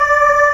Index of /90_sSampleCDs/AKAI S6000 CD-ROM - Volume 1/VOCAL_ORGAN/BIG_CHOIR
AH VOICE  -L.WAV